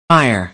us_phonetics_sound_fire_2023feb.mp3